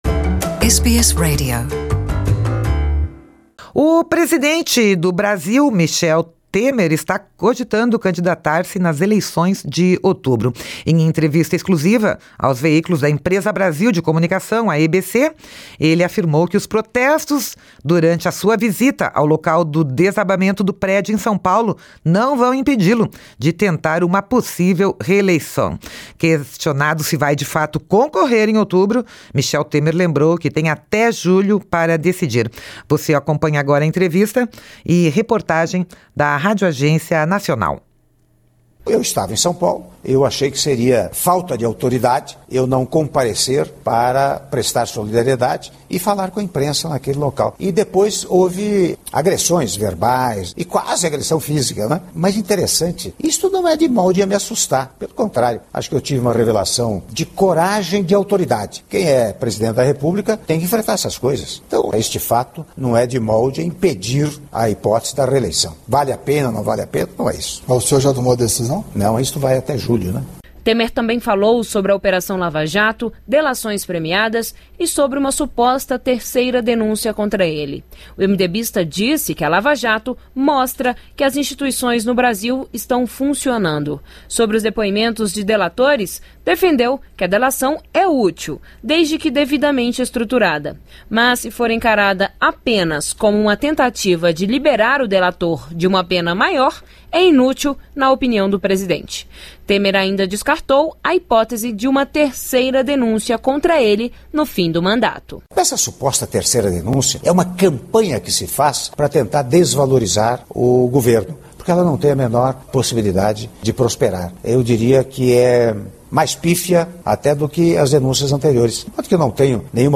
Em entrevista exclusiva aos veículos da Empresa Brasil de Comunicação/EBC, o presidente do Brasil, Michel Temer, afirmou que os protestos durante a visita dele ao local do desabamento do prédio em São Paulo não vão impedi-lo de tentar uma possível reeleição. Ouça aqui a reportagem da Radioagência nacional.